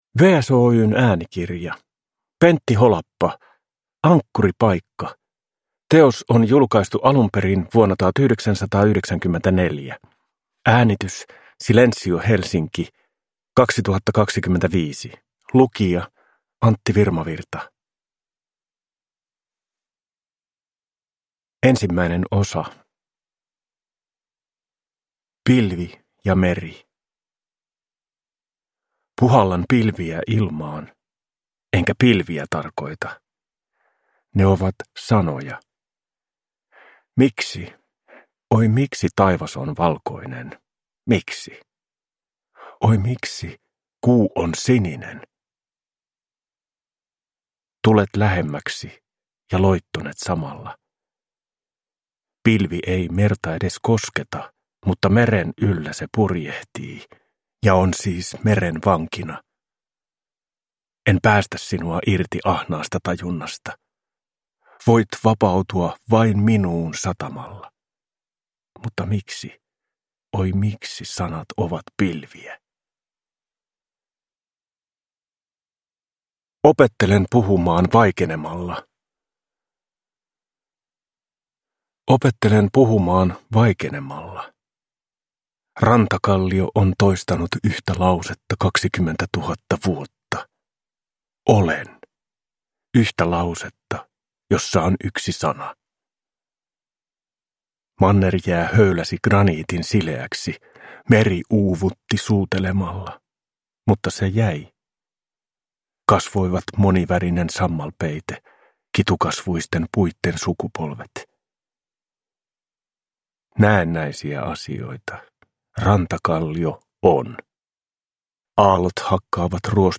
Ankkuripaikka – Ljudbok
Uppläsare: Antti Virmavirta